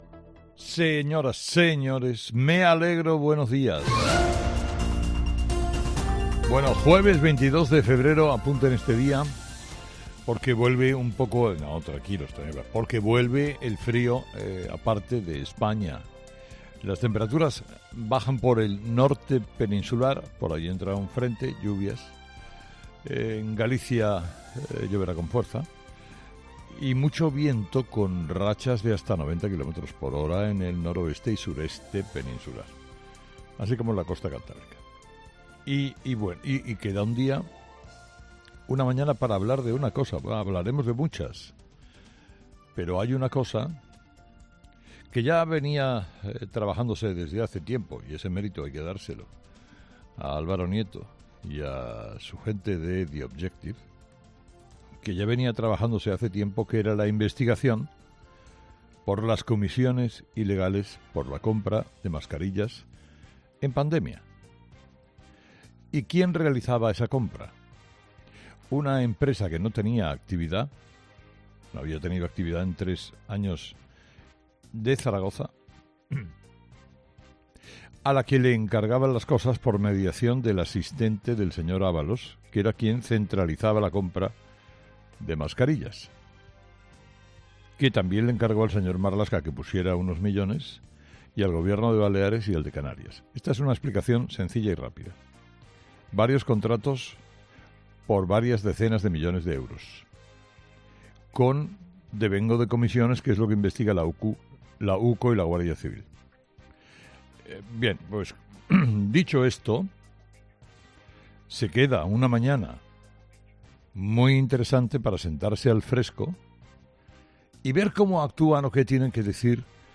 Escucha el análisis de Carlos Herrera a las 06:00 en Herrera en COPE del jueves 22 de febrero